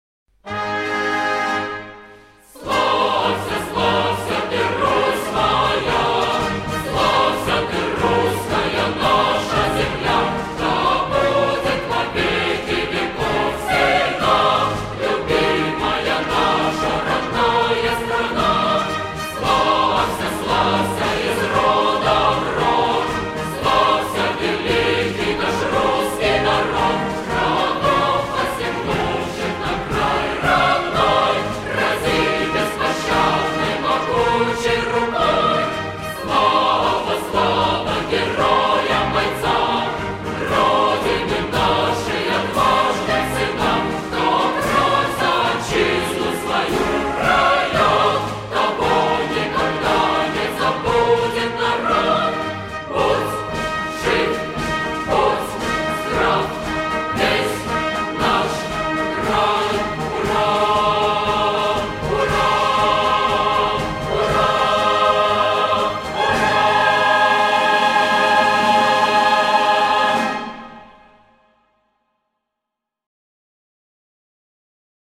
Slavsya.ogg  (размер файла: 1,14 Мб, MIME-тип: application/ogg ) Хор «Славься!»